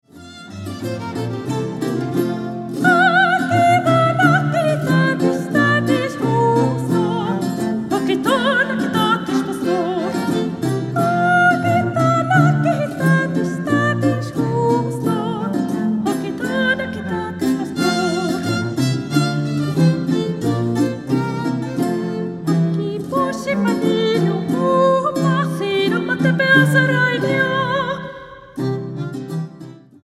Aria 1 Dúo